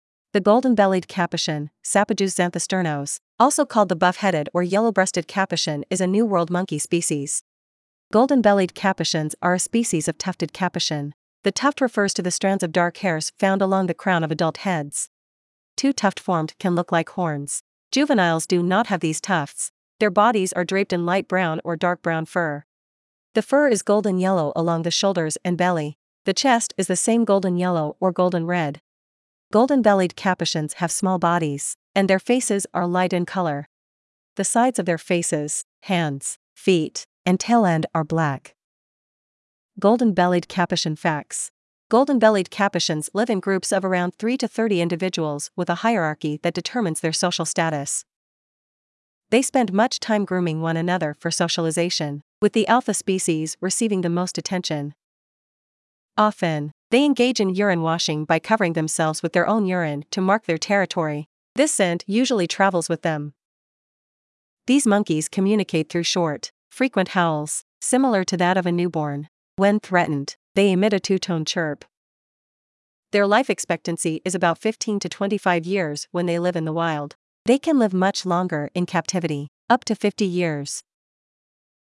Golden-Bellied Capuchin
• These monkeys communicate through short, frequent howls, similar to that of a newborn. When threatened, they emit a two-tone chirp.
golden-bellied-capuchin.mp3